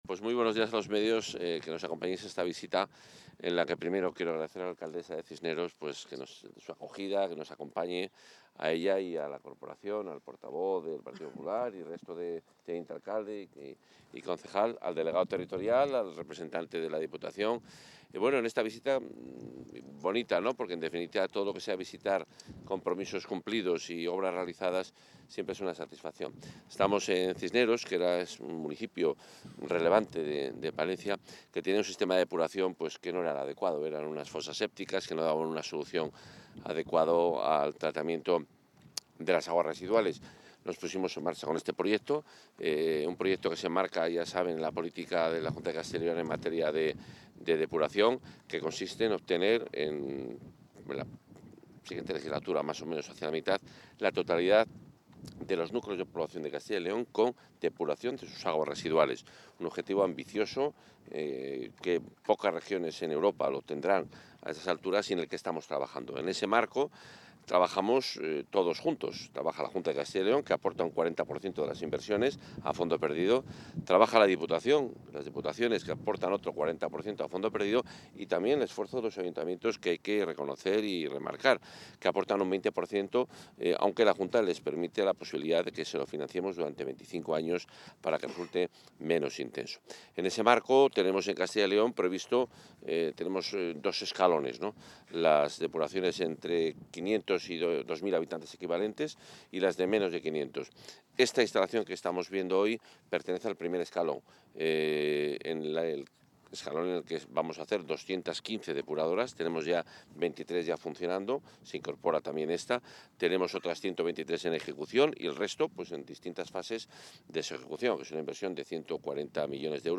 Intervención del consejero.
El consejero de Medio Ambiente, Vivienda y Ordenación del Territorio, Juan Carlos Suárez-Quiñones, ha visitado hoy las instalaciones d ela EDAR de Cisneros, en Palencia, acompañado de la alcaldesa del municipio y miembros de la corporación municipal, así como de técnicos de la obra. La puesta en marcha de esta nueva depuradora de aguas residuales pone de manifiesto el compromiso de la Junta de Castilla y León con el desarrollo sostenible.